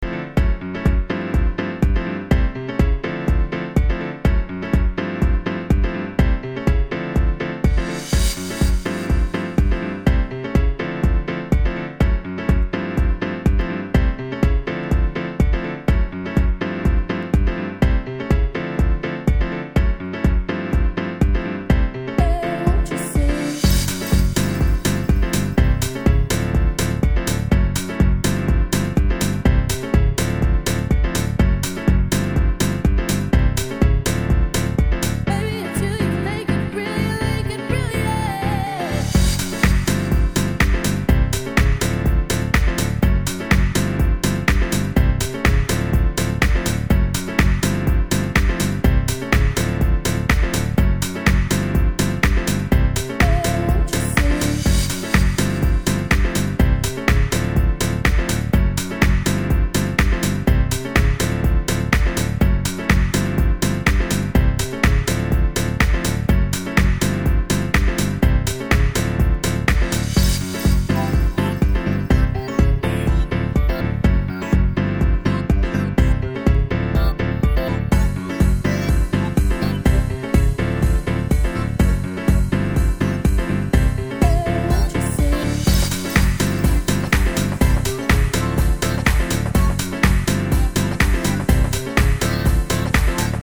Disco House